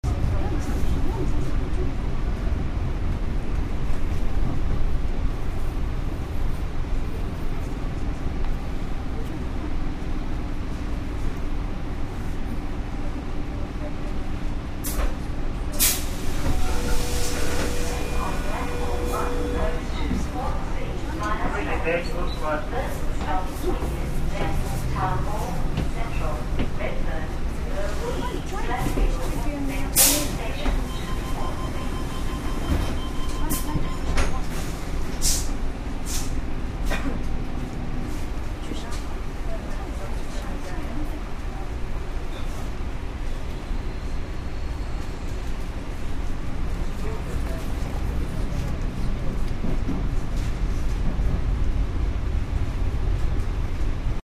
I have several hours of nice field recordings on mini-disc, some of which will eventually make it up here as mp3s, but this will happen gradually.
• On the train from Newtown, headed for Townhall Station, Sydney. September 21.
train_from_Newtown.mp3